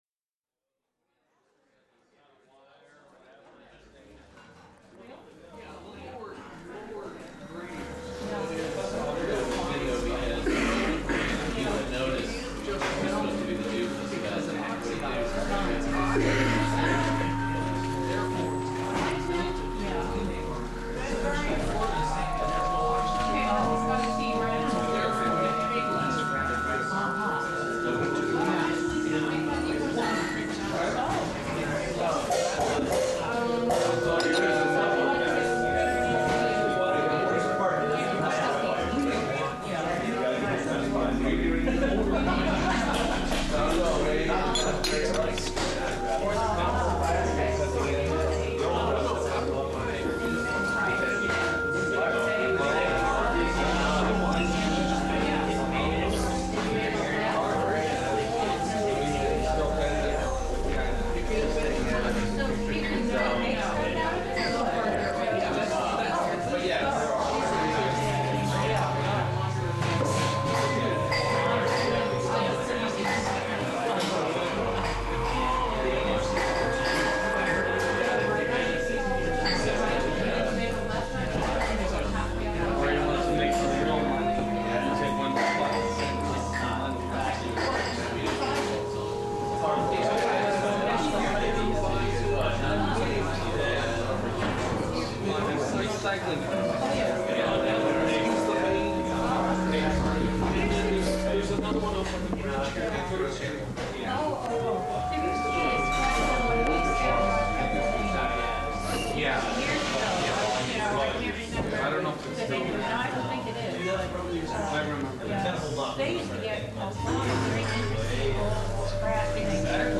I then recorded the system harmonizing with ambient sounds.
Here's another excerpt from the same place. Slightly different settings. Some music in the background.